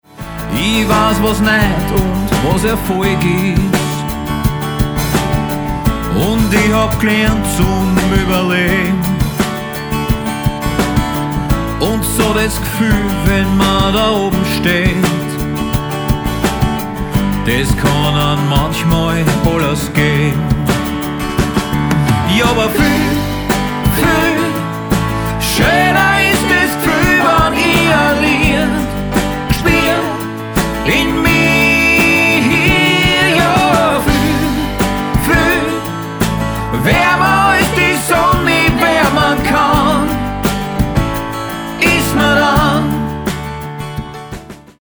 Gesang
Gesang & Schlagzeug
Gitarre
Keyboards
E-Bass